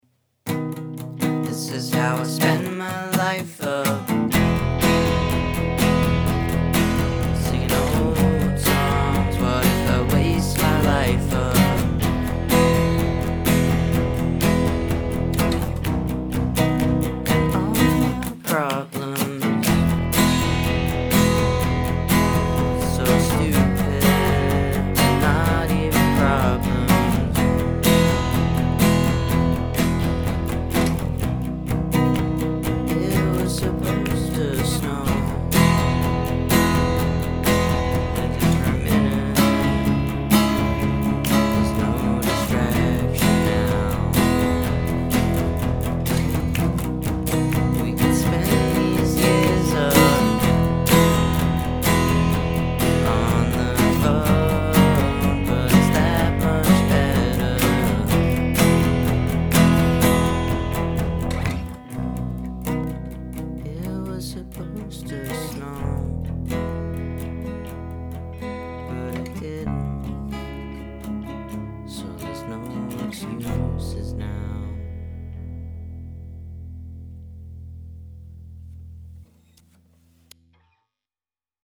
altfolk